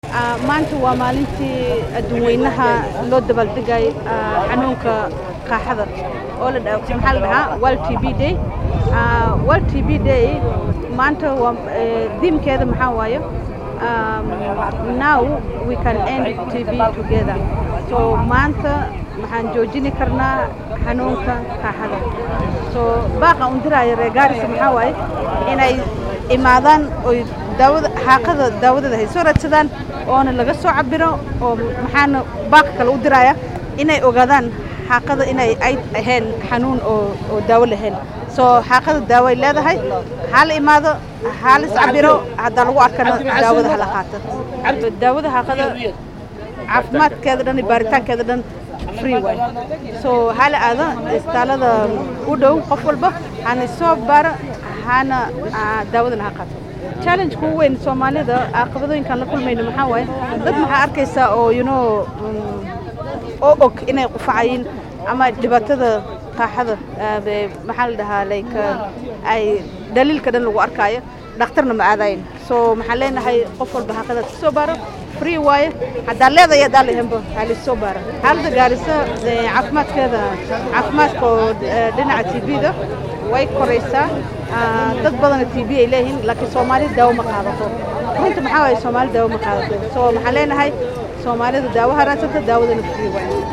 Munaasabad lagu xusayey maalinta qaaxada ayaa lagu qabtay magaalada Garissa waaxana ka soo qeyb galay qeybaha kala duwan ee bulshada. Bulshada ayaa lagu wacyigeliyay inay iska daaweeyaan xanuunka qaaxada.